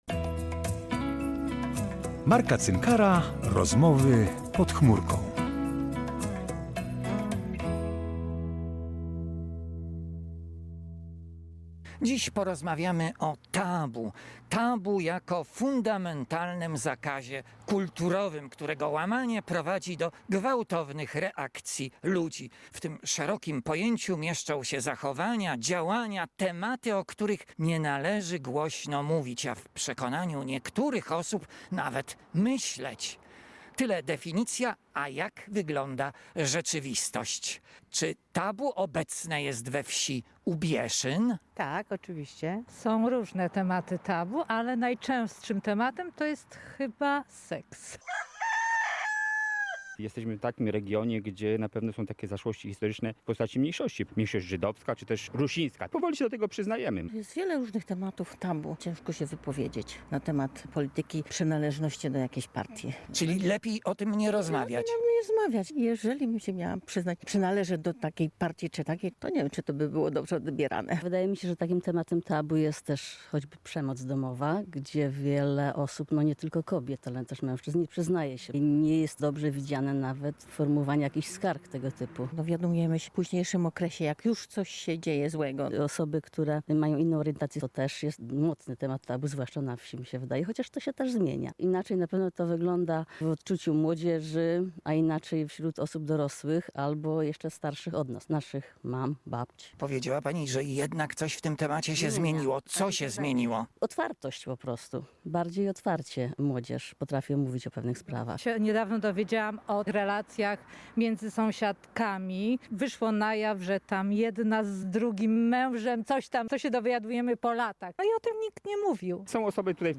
rozmowy pod chmurką